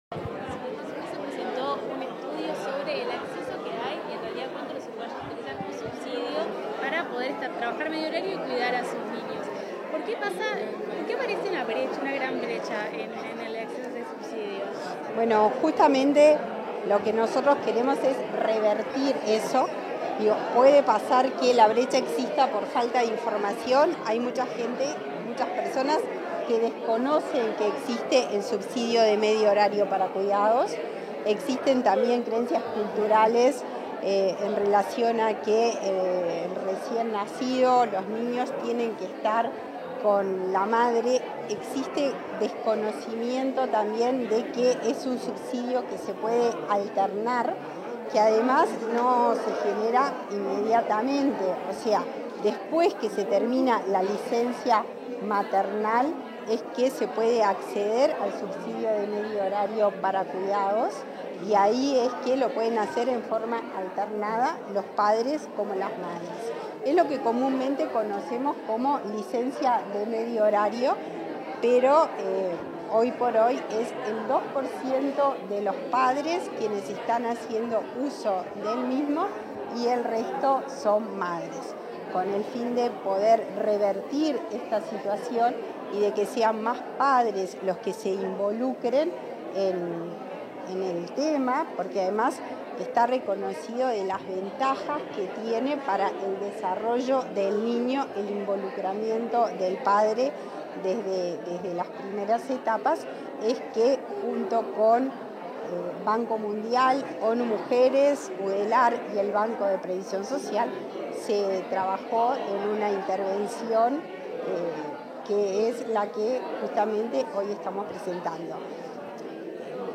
Declaraciones a la prensa de la directora del BPS, Daniela Barindelli